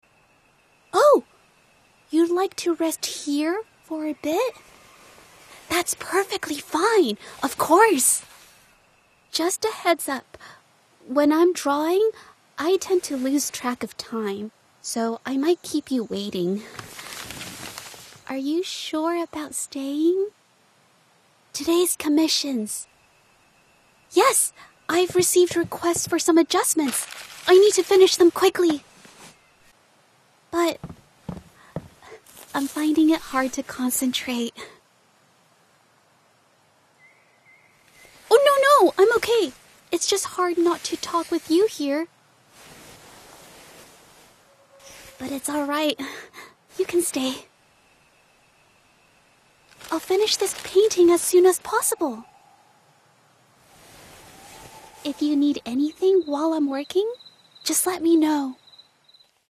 Video Games Showreel
Female
American Standard
Bright
Warm
Youthful